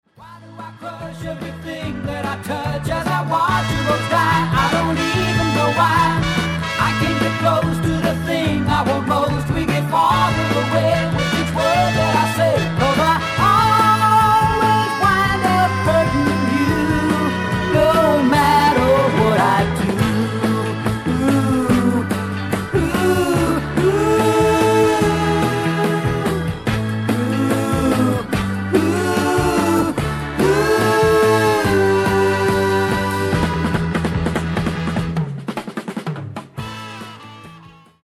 SOFT ROCK / PSYCHEDERIC POP